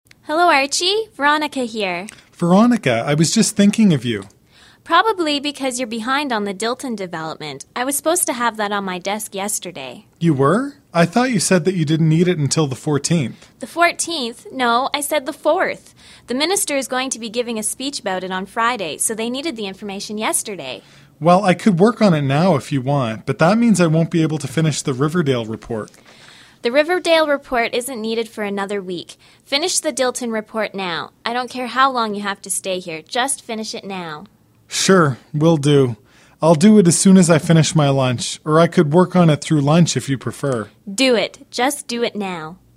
Écoutez le dialogue deux fois, faites un résumé et ensuite répondez aux questions de suivi (Durée: 11-13min) [tabs] [tab title=”Dialogue”] “Security Clearance “ Security clearance